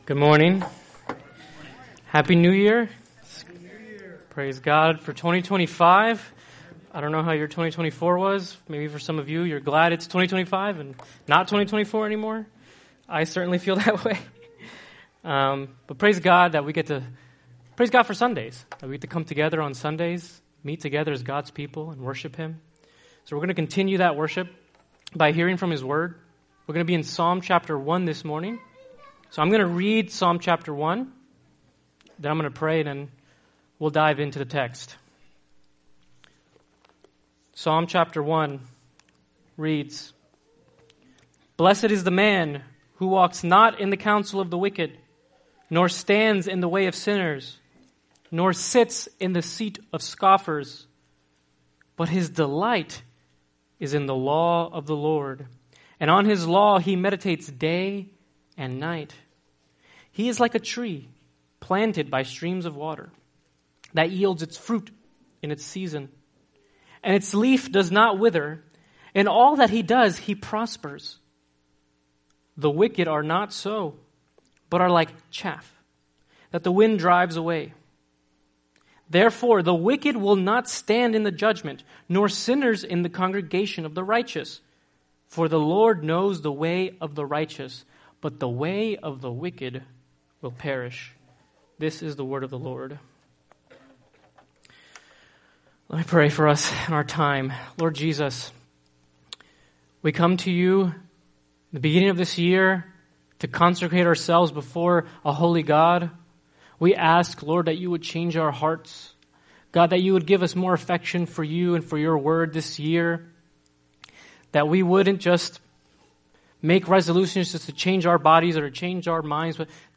Series: One-Off Sermons